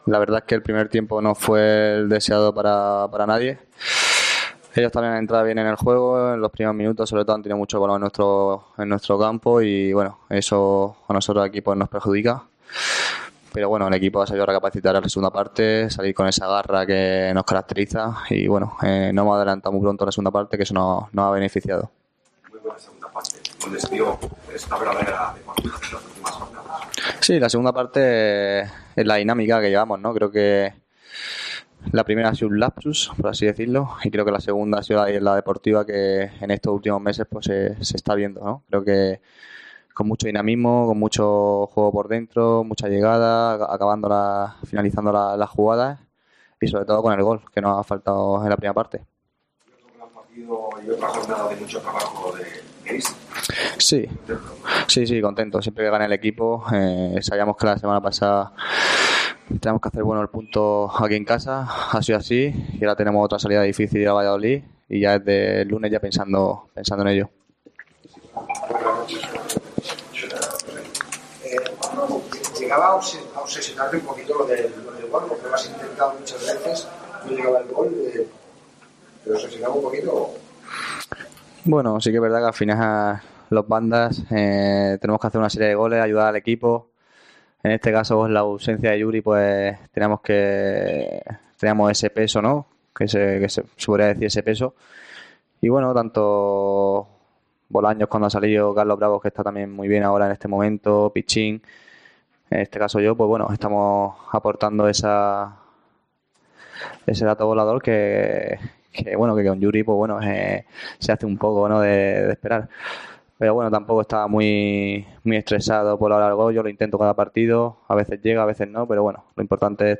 Escucha aquí las palabras de los dos jugadores blanquiazules